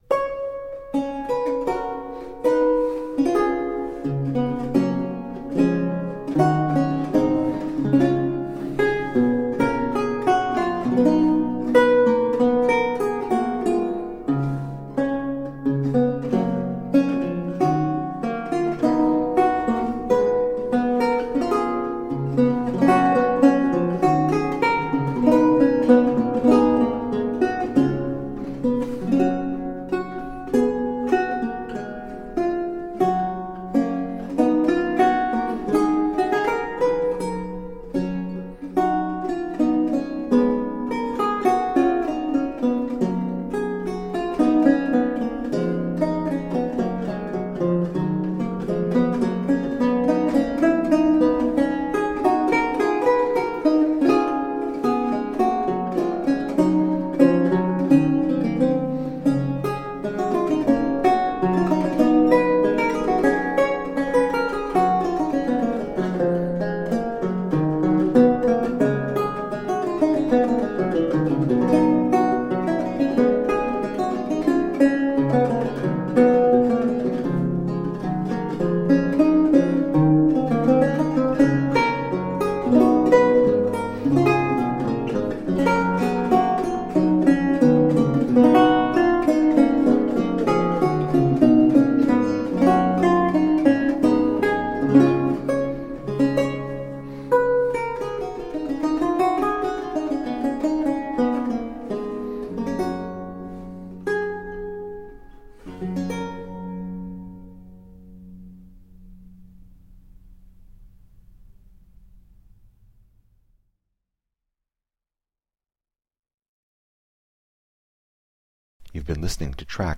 Lute music of 17th century france and italy.